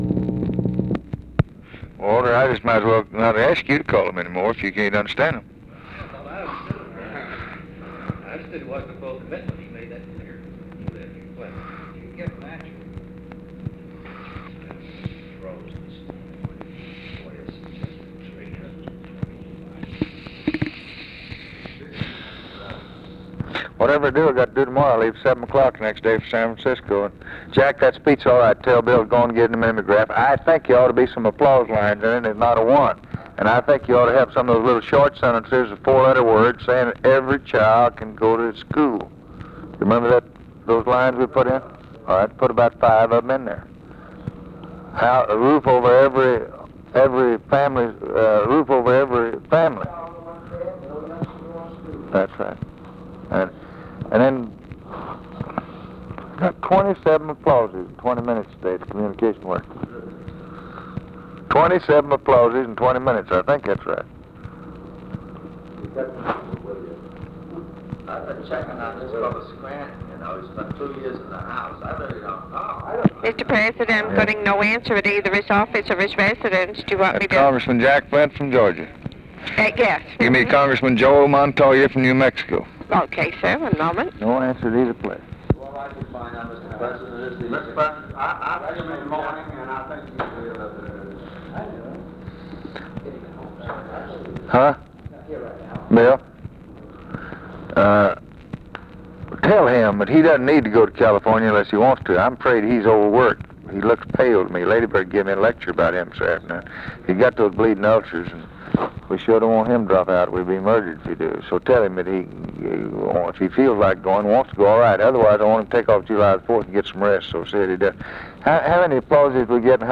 Conversation with OFFICE CONVERSATION
Secret White House Tapes